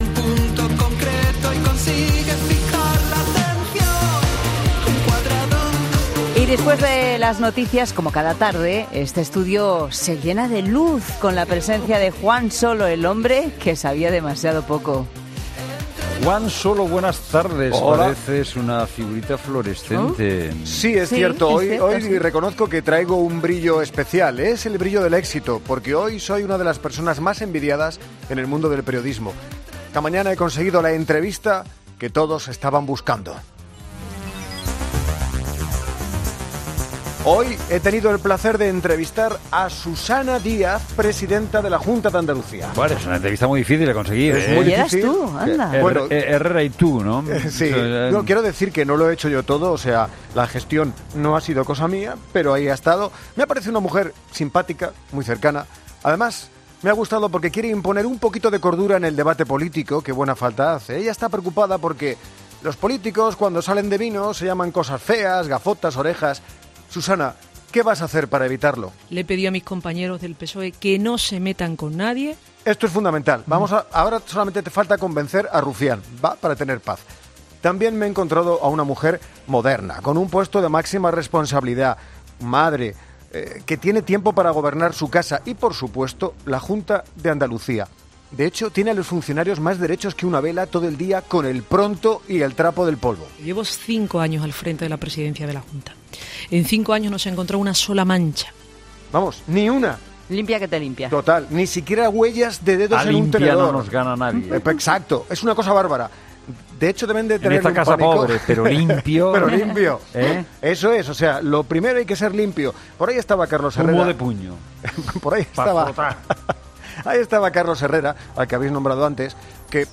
Humor: 'El hombre que sabía demasiado poco'